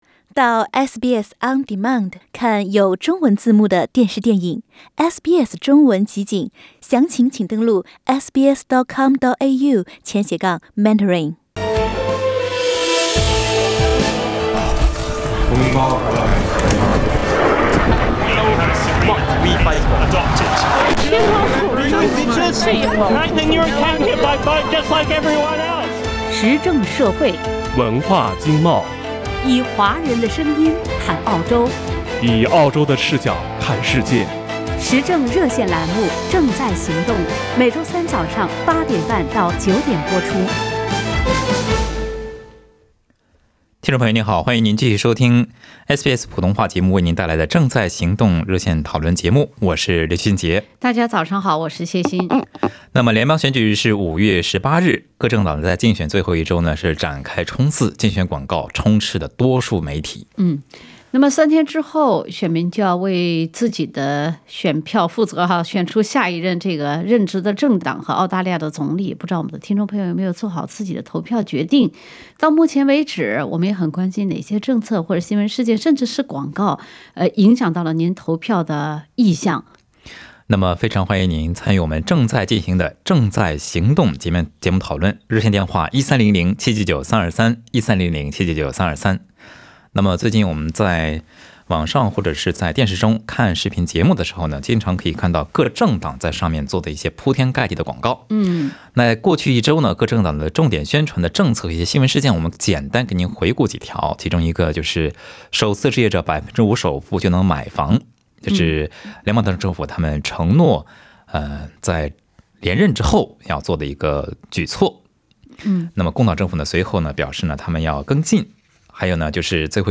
本期《正在行动》中，拨打电话参与节目的大多数听众表示，经济是他们最关注的政策。有听众说，他们会根据此前政府所做的而不是所承诺的来决定投票取向。